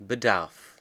Ääntäminen
Synonyymit pénurie carence Ääntäminen France: IPA: [œ̃ mɑ̃k] Tuntematon aksentti: IPA: /mɑ̃k/ Haettu sana löytyi näillä lähdekielillä: ranska Käännös Ääninäyte Substantiivit 1.